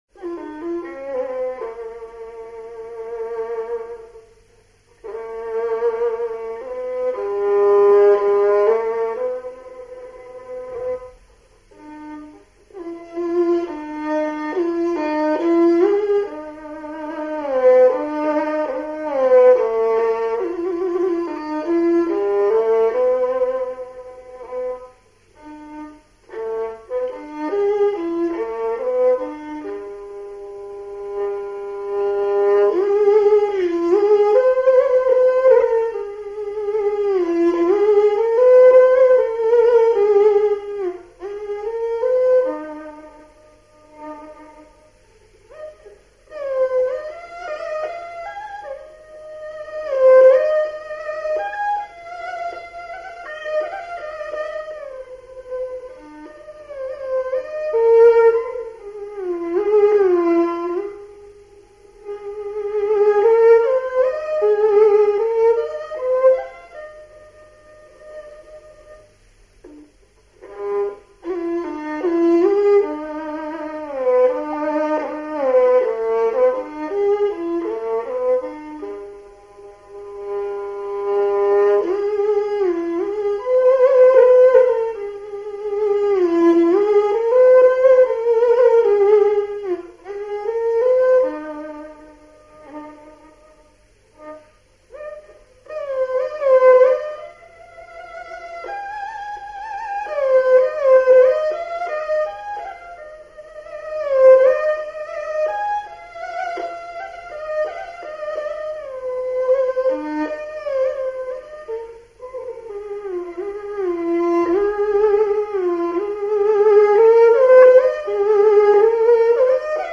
The Moon Reflected over Two Ponds - Chinese Classical Music
This work has two themes, which complement and intertwine, and finally melt into each other subtly and smoothly. Step by step and variation upon variation, the two themes rise and fall effortlessly.
There is a profound range of feeling in this piece, which incorporates a majestic spirit within a tightly knit composition. Vigorous variations in bowing technique make full use of the five hand positions, and the result is a fiercely emotional coloring expressing of the composer's suppressed grief at having tasted to the full the bitterness of life in the old society. The Moon Reflected over Two Ponds is an exquisite example of Chinese instrumental folk music stemming from the heart of a small-town folk artist.